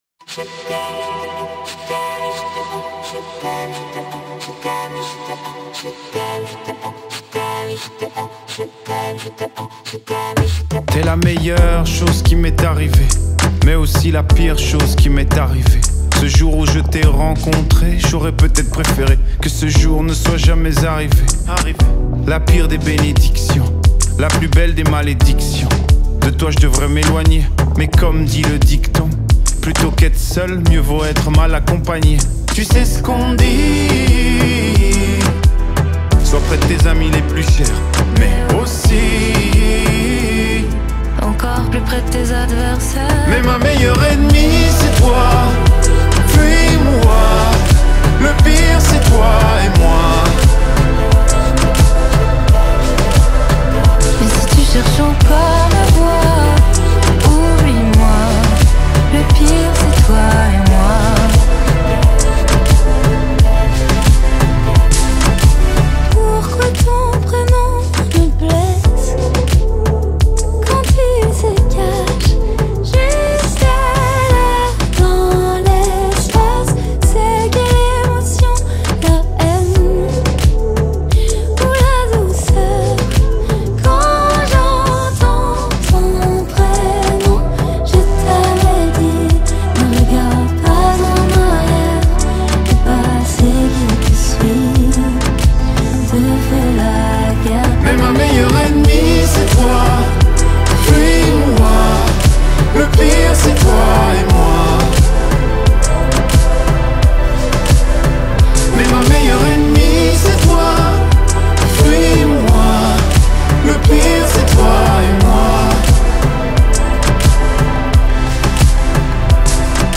پاپ و الکتریک پاپ